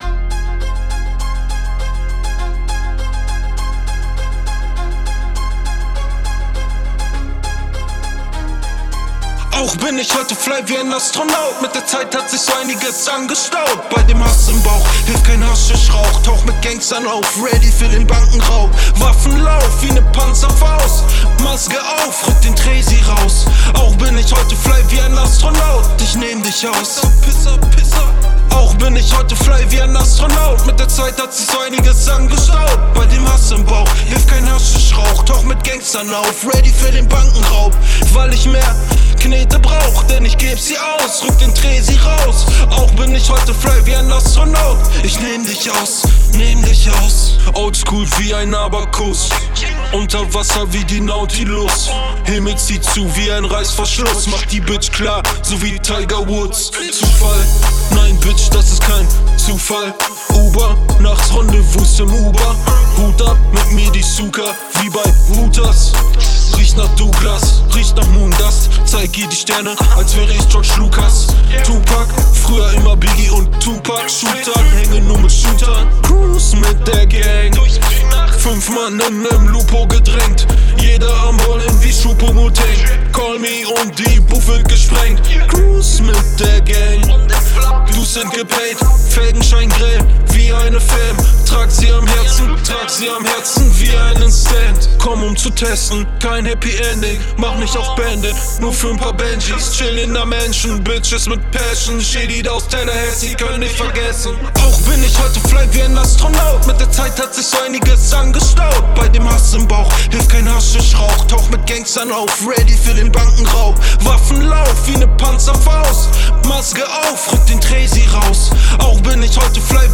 TLM 103 oder Alternative
Für den Rap- Bereich soll nur der amtlich sein: ;-)
Einmal die Rohrversion und das fertige Produkt Anhänge astronaut hook.wav astronaut hook.wav 39 MB Astronaut_DBR_Master24bit.wav Astronaut_DBR_Master24bit.wav 39 MB